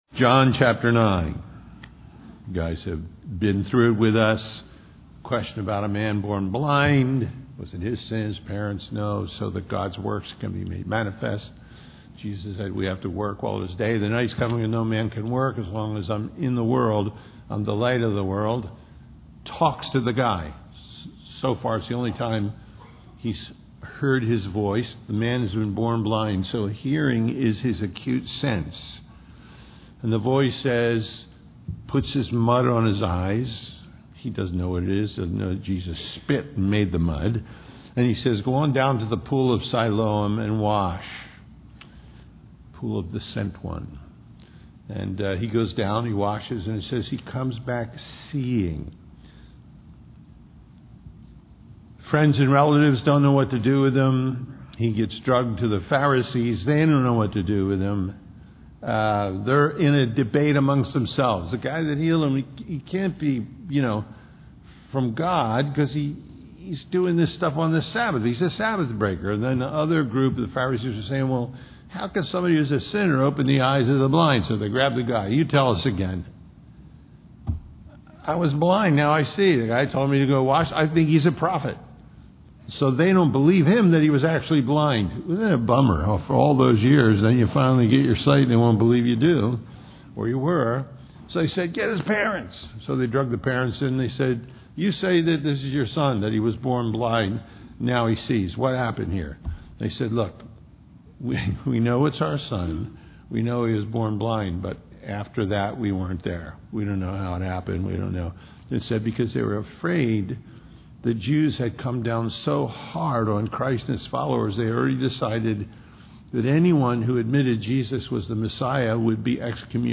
John 9:35-9:41 The Final Verdict Listen Download Original Teaching Email Feedback 9 Jesus heard that they had cast him out; and when he had found him, he said unto him, Dost thou believe on the Son of God?